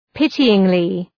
Προφορά
{‘pıtııŋlı} (Επίρρημα) ● οικτιρμόνως